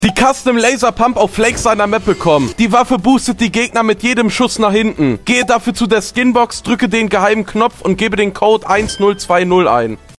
Die Custom Laser Pump auf sound effects free download